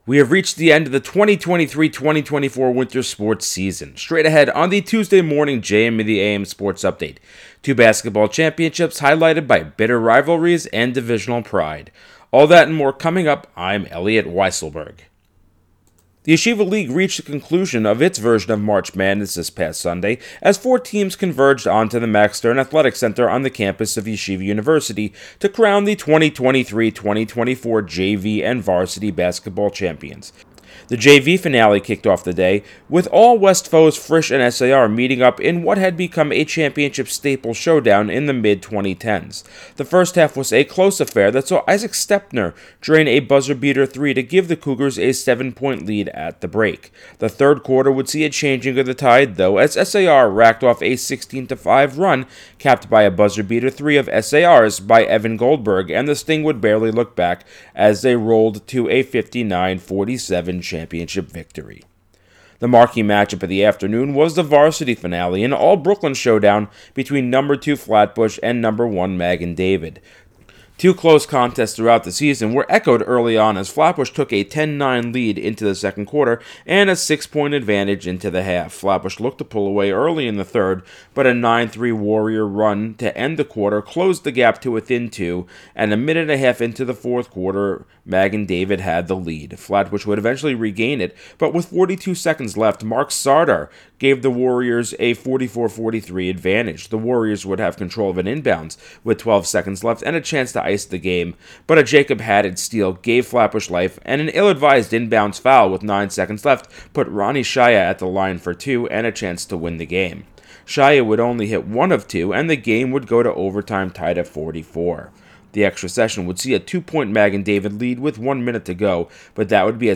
News, Sports